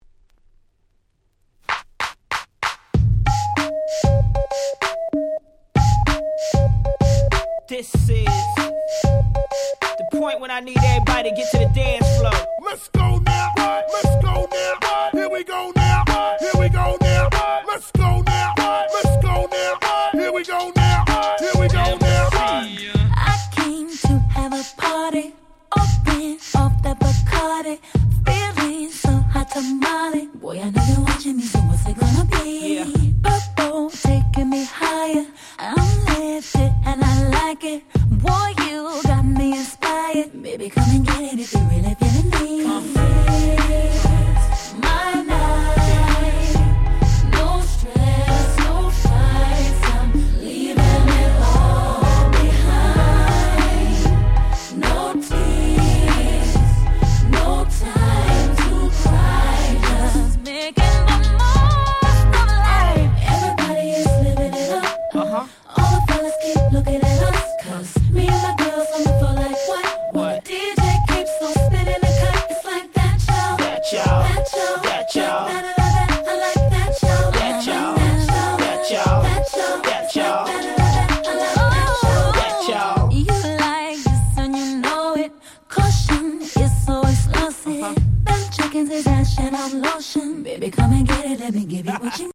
05' Big Hit R&B !!